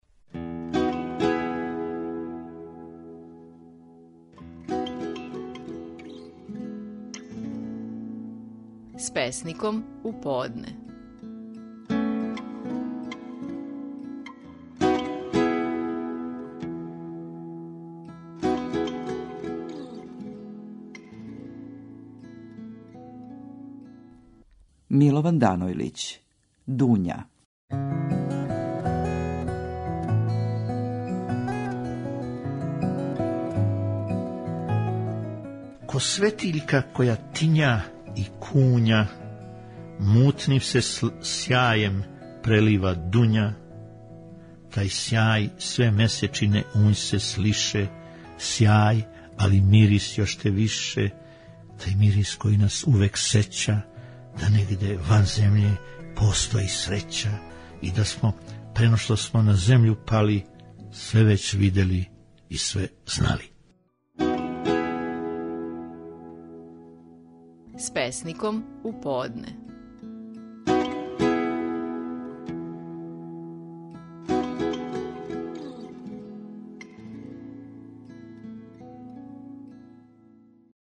Наши најпознатији песници говоре своје стихове
Милован Данојлић говори своју песму „Дуња”.